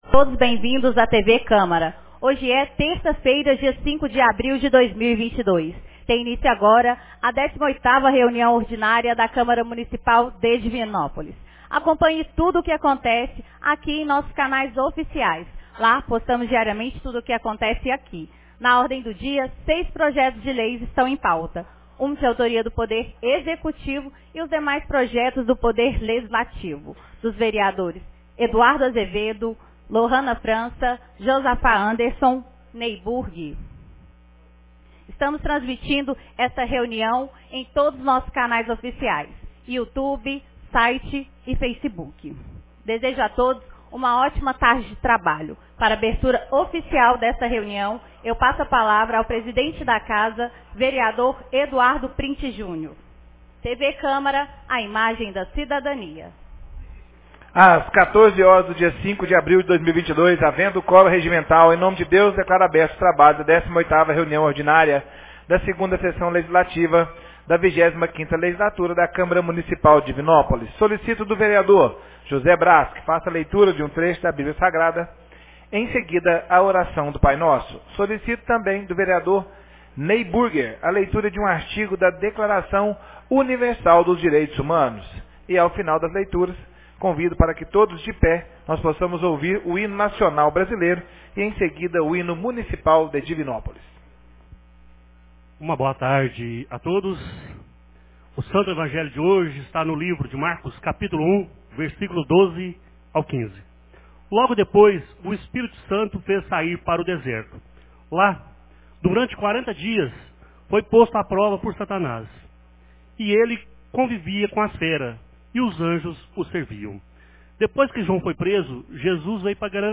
18ª Reunião Ordinária 05 de abril de 2022